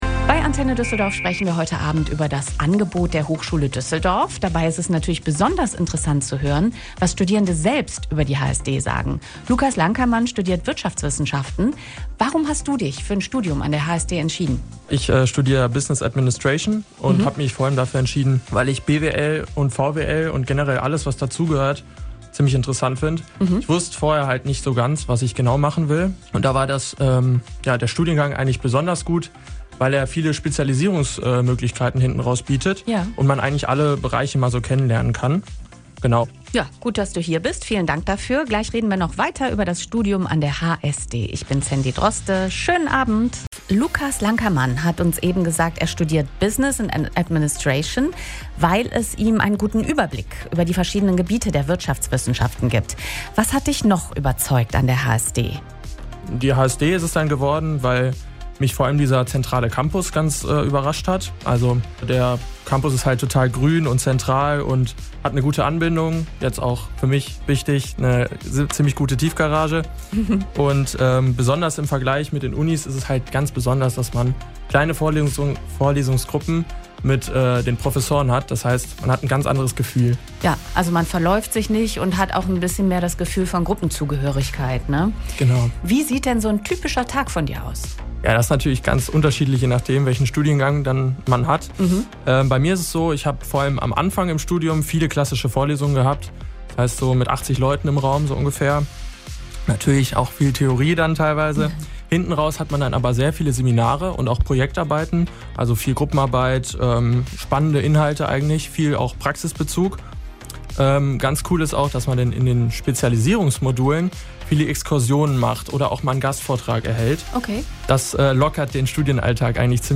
Das HSD-Team war am 12.06.2025 bei Antenne Düsseldorf zu Gast und hat einiges über den Tag der offenen Tür erzählt.